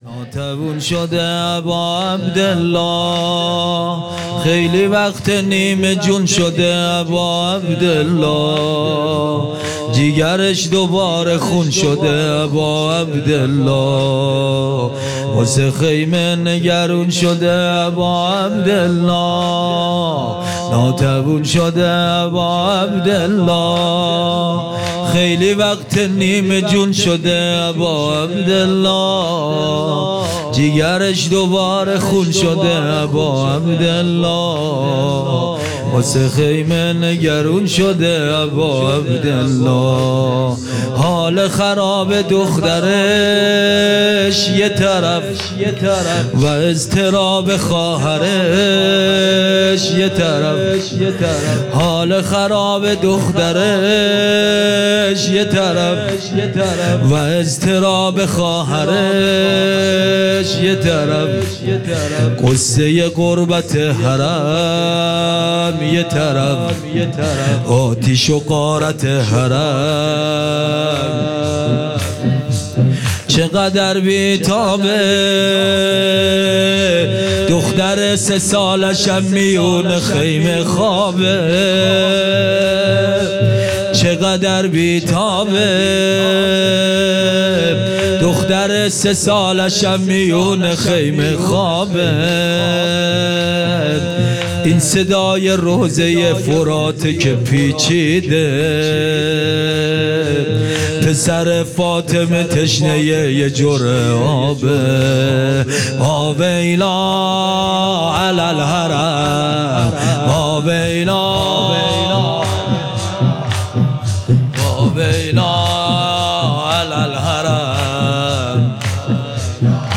شب شام غریبان محرم الحرام سال ۱۴۴۷